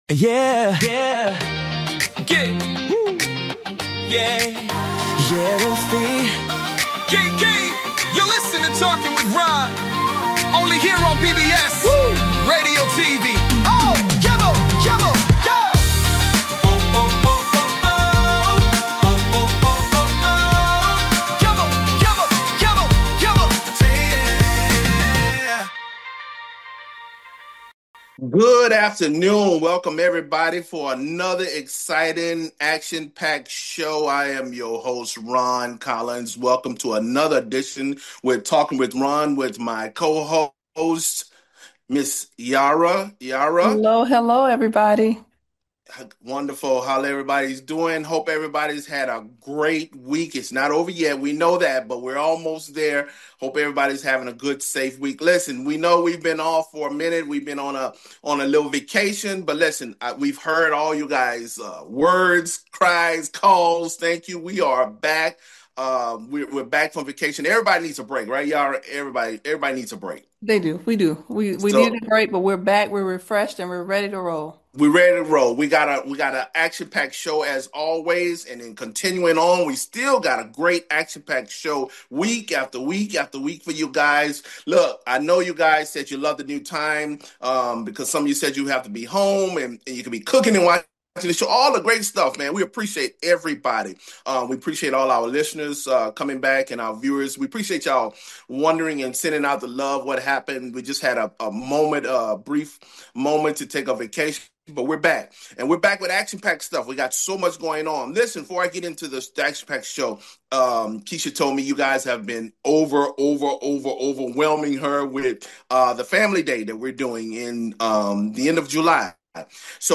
Talk Show Episode, Audio Podcast
LIVE BROADCAST